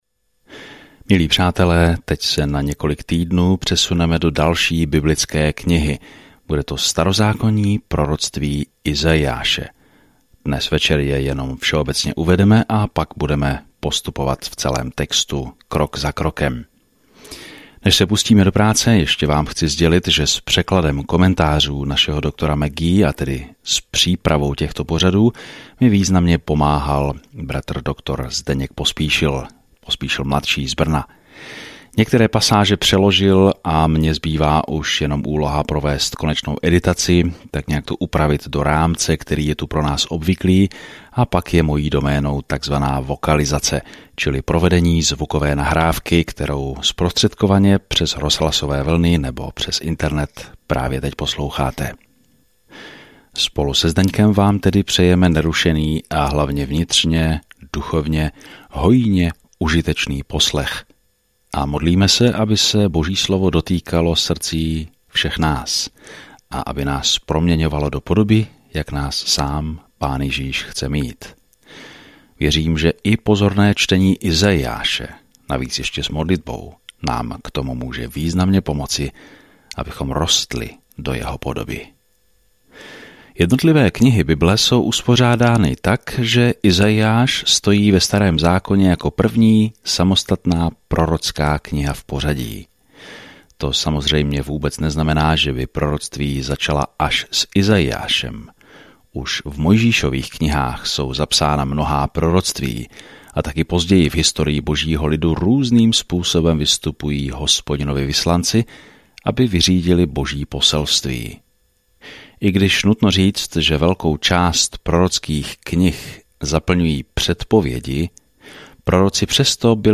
Písmo Izaiáš 1:1 Začít tento plán Den 2 O tomto plánu Izajáš, nazývaný „páté evangelium“, popisuje přicházejícího krále a služebníka, který „ponese hříchy mnohých“ v temné době, kdy Judu dostihnou političtí nepřátelé. Denně procházejte Izajášem a poslouchejte audiostudii a čtěte vybrané verše z Božího slova.